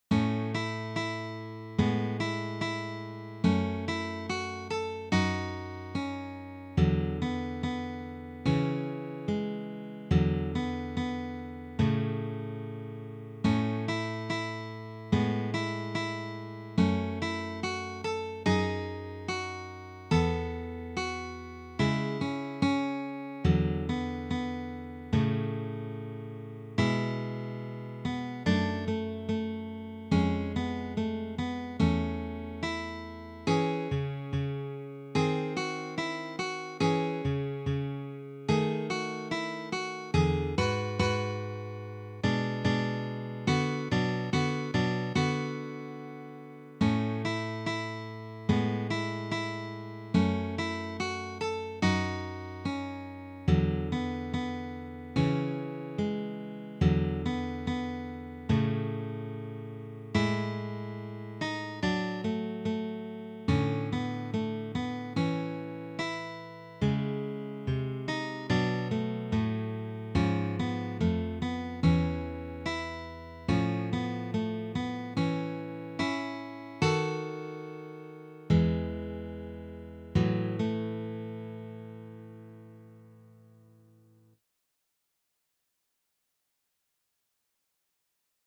arranged for three guitars
This folk selection is arranged for guitar trio.